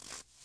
added base steps sounds
snow_2.ogg